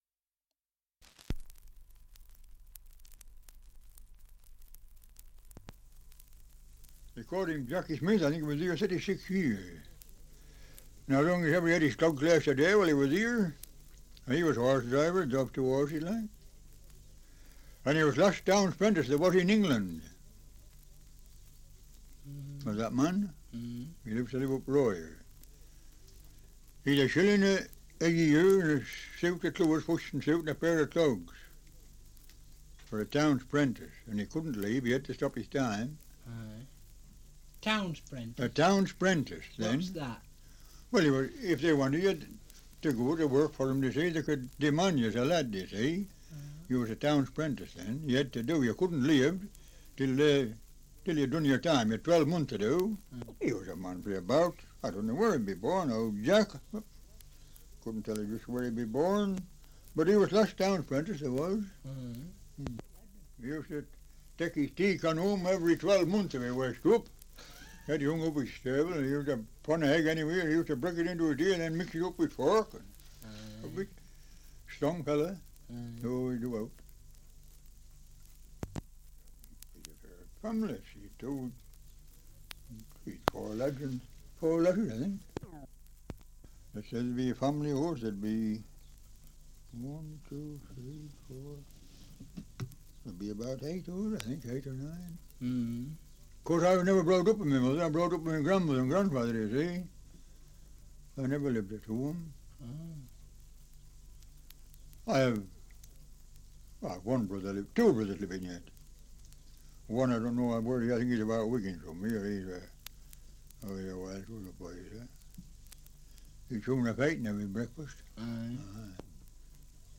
Survey of English Dialects recording in Dolphinholme, Lancashire
78 r.p.m., cellulose nitrate on aluminium